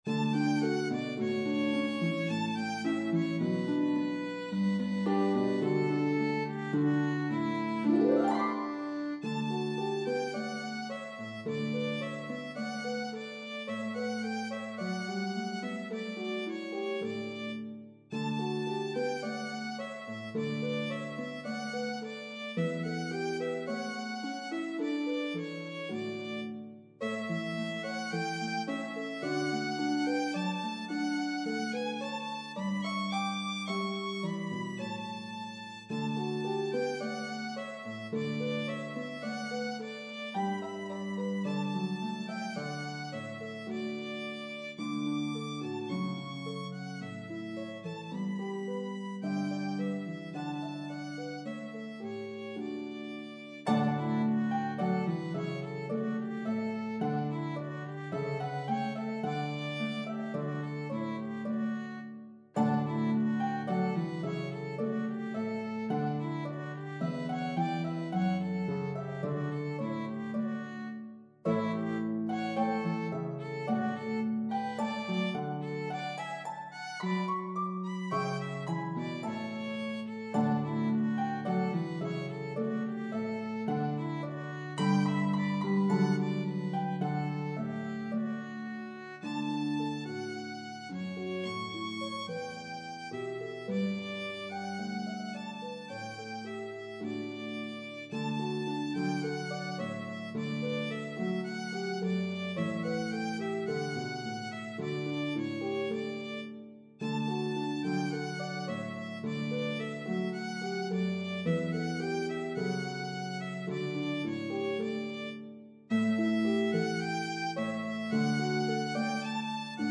as a duet for lever harp
Harp and Violin version